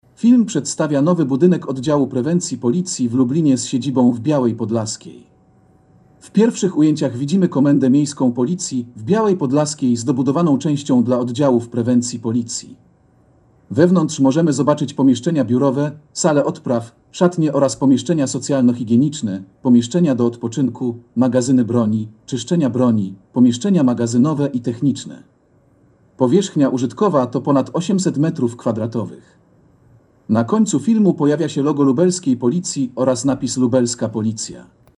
Nagranie audio Audiodeskrypcja Filmu Oddział Prewencji Policji z siedzibą w Białej Podlaskiej